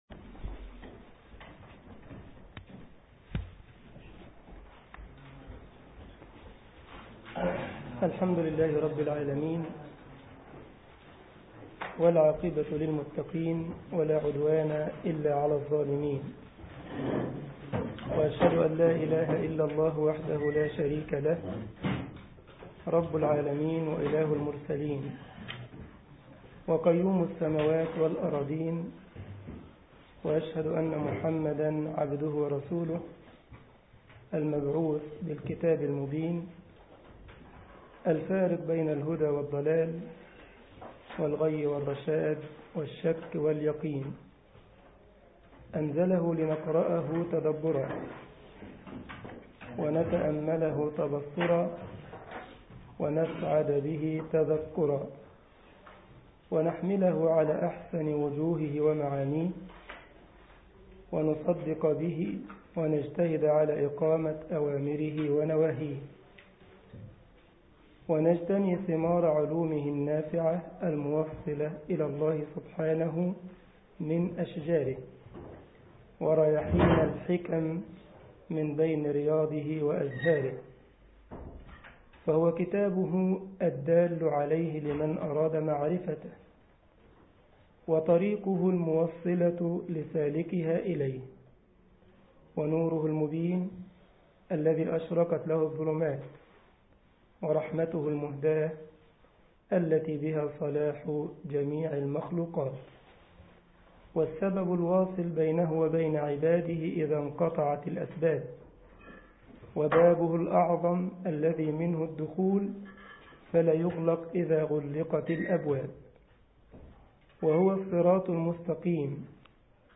الجمعية الإسلامية بالسارلند ـ ألمانيا درس الخميس